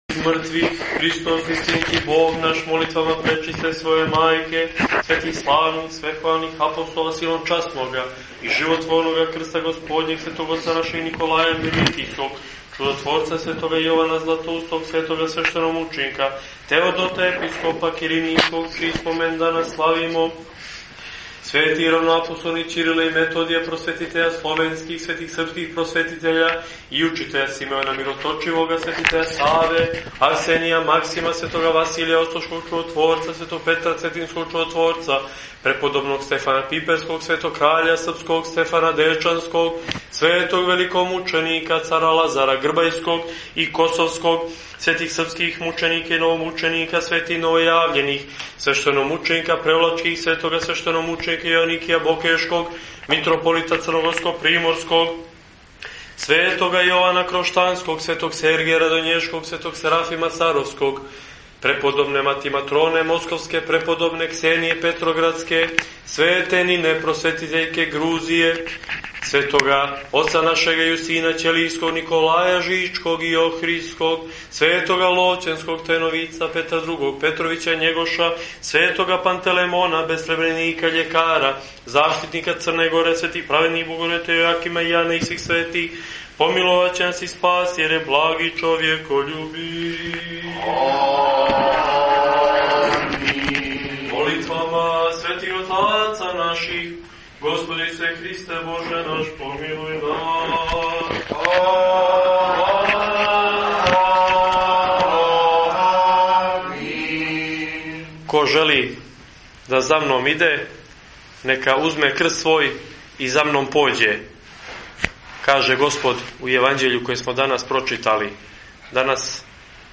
Његову бесједу изговорену на овој литургији послушајте и преузмите овдје: